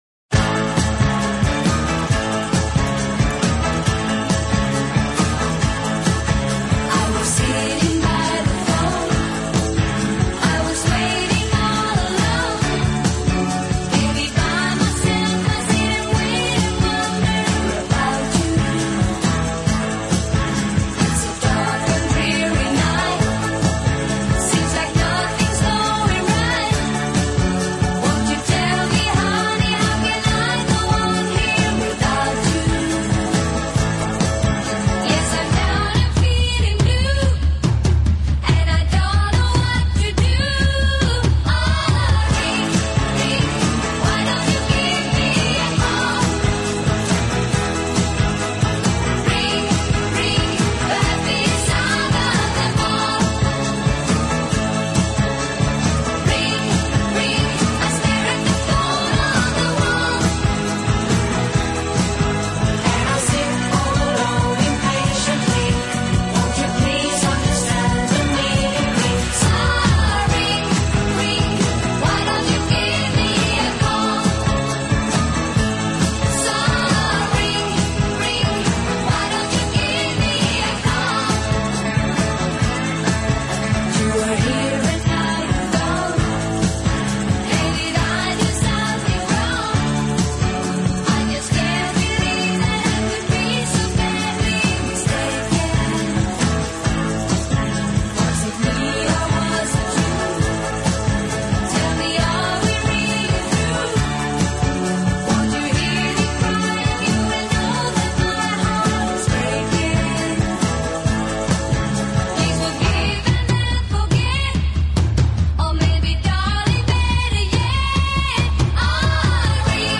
音乐风格：流行|流行/摇滚|(Pop/Rock)